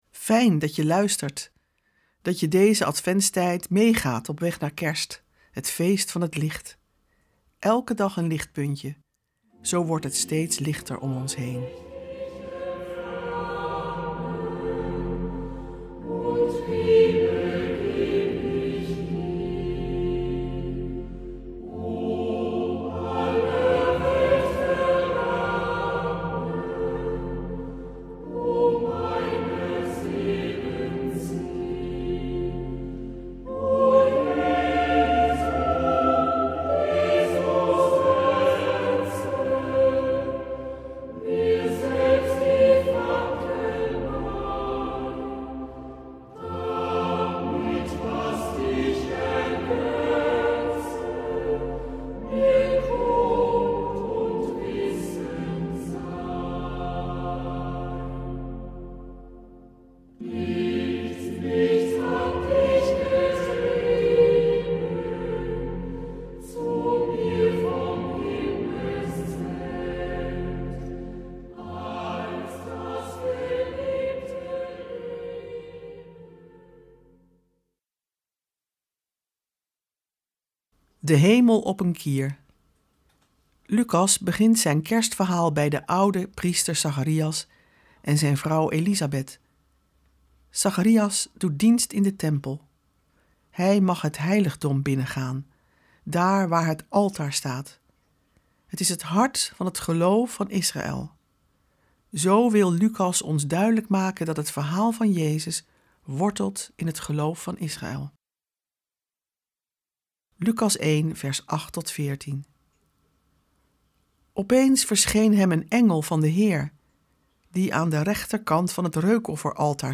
Meditaties in de Adventstijd